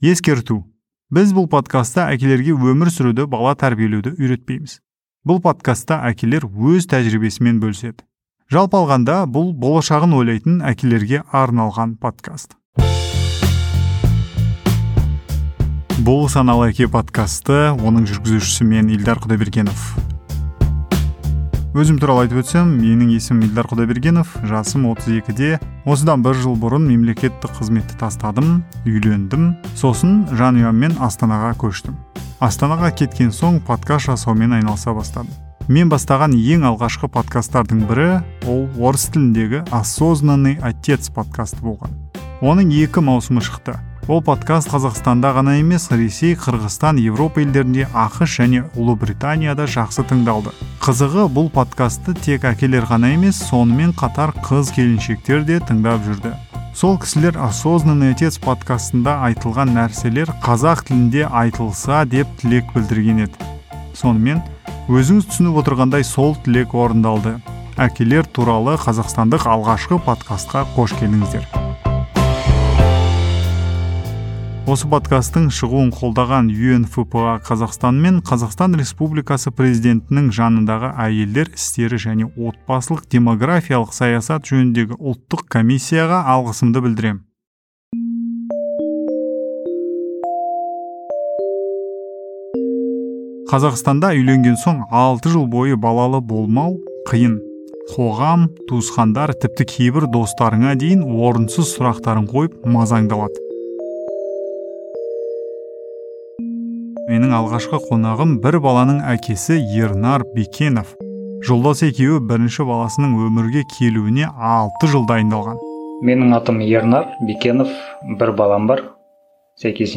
Play in new window Ескерту: сұқбат 2021 жылы алынған.